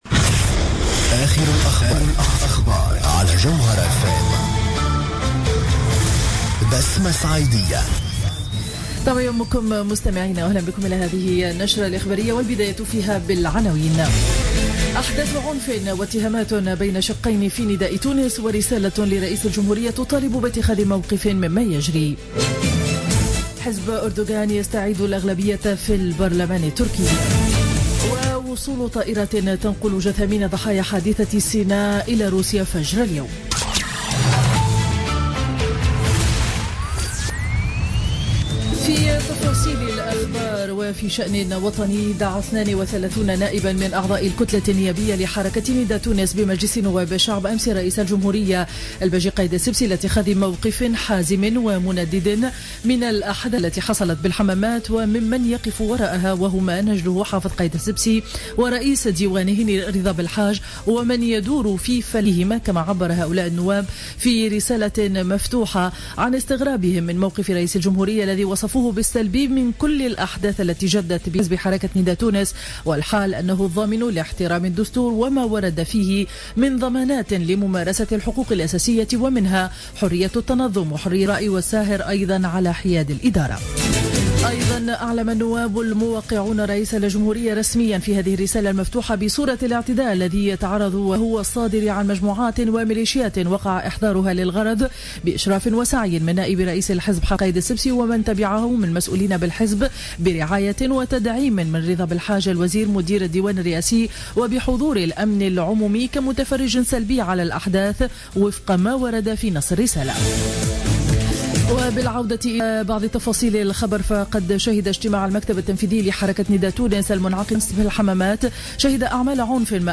نشرة أخبار السابعة صباحا ليوم الاثنين 02 نوفمبر 2015